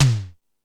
Lazer Drums(42).wav